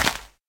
assets / minecraft / sounds / dig / grass3.ogg
grass3.ogg